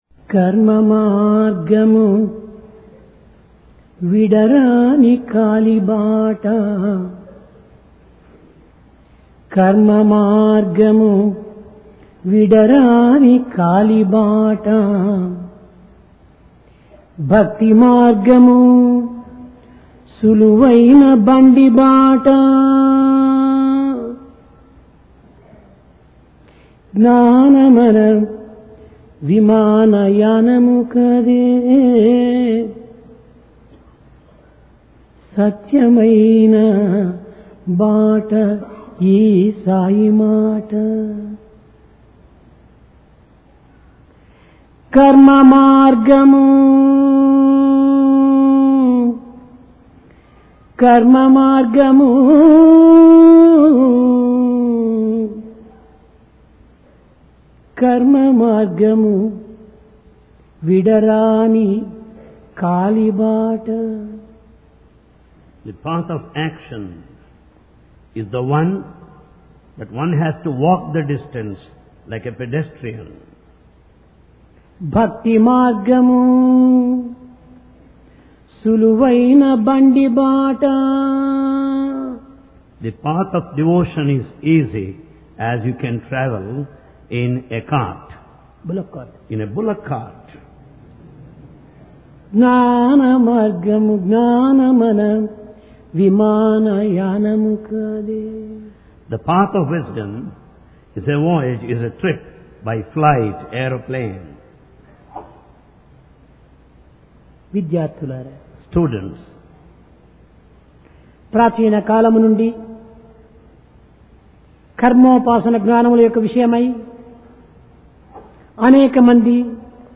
PRASHANTI VAHINI - DIVINE DISCOURSE 29 JUNE, 1996